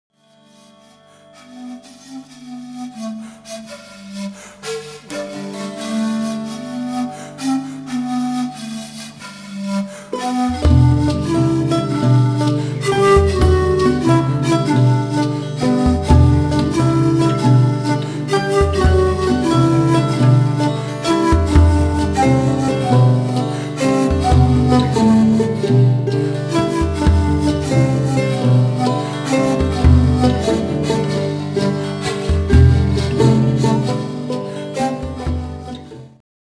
フォルクローレミュージックのバンドを組んでいます。
（インストゥルメンタル：ボリビア伝承曲）